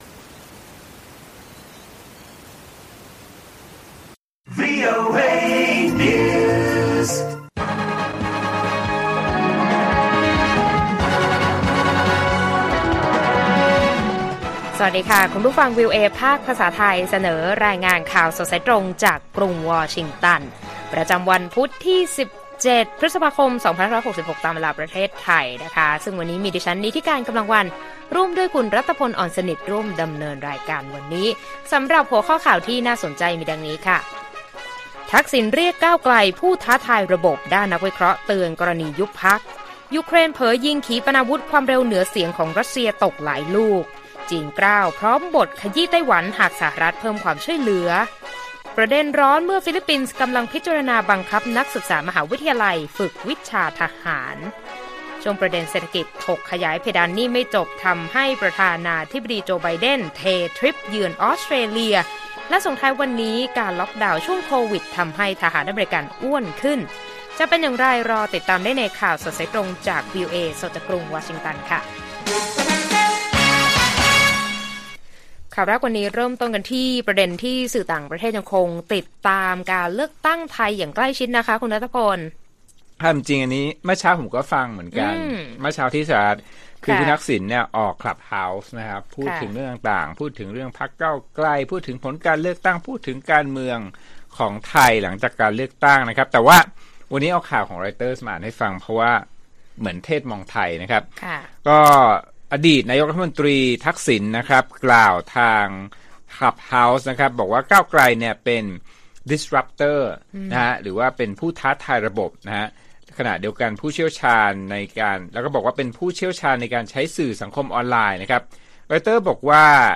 ข่าวสดสายตรงจากวีโอเอ ไทย พุธ 17 พฤษภาคม 2566